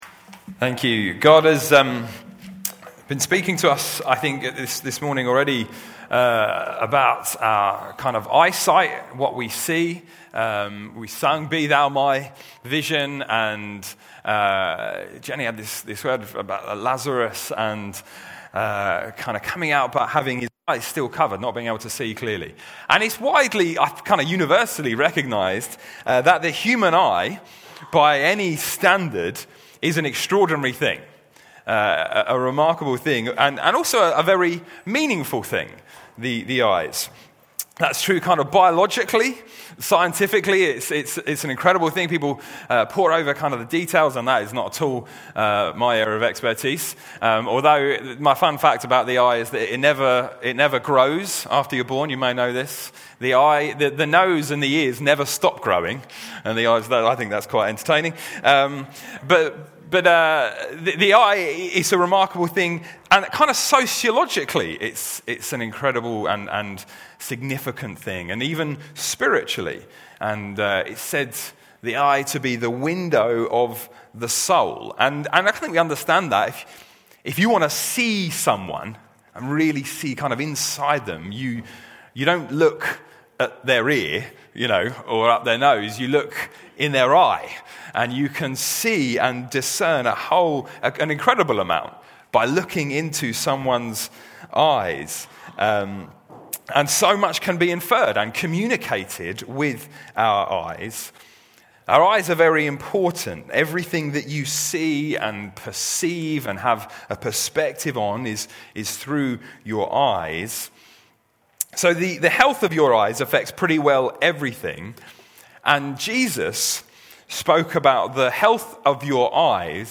Grace Church Sunday Teaching